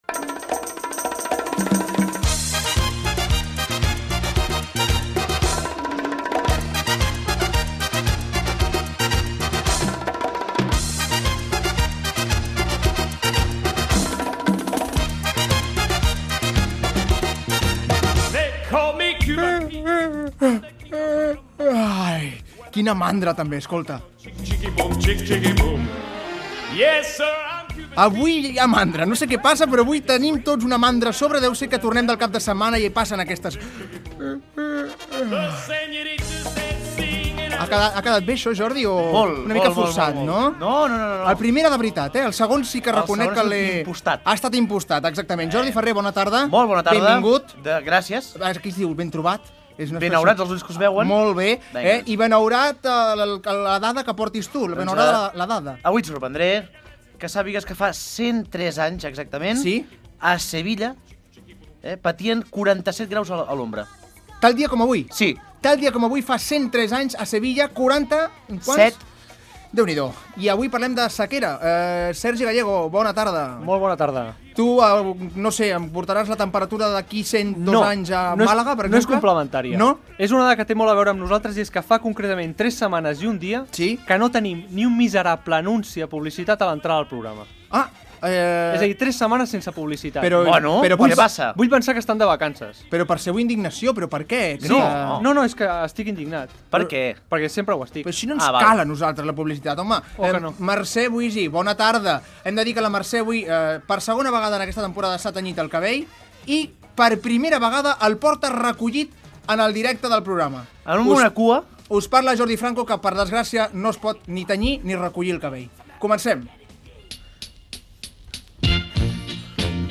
Inici del programa humorístic.
Entreteniment
FM